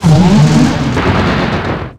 Cri de Fulguris dans Pokémon X et Y.